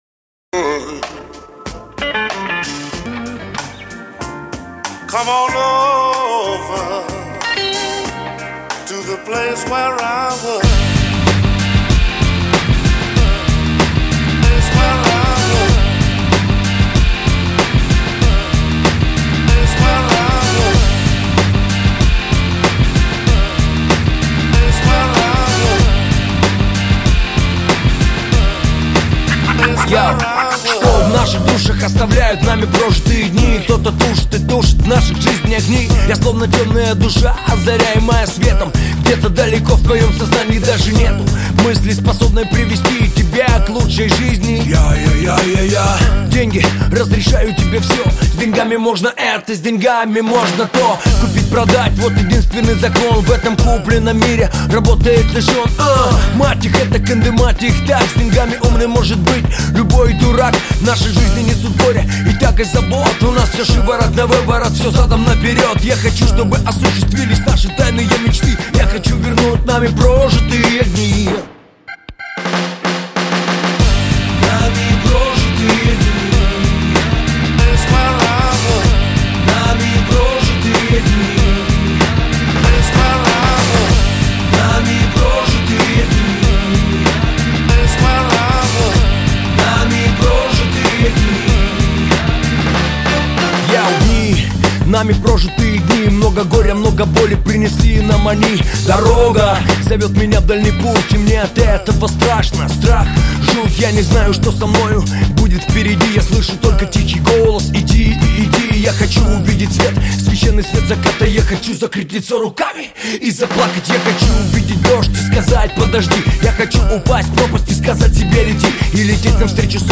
Жанр: Русский рэп / Хип-хоп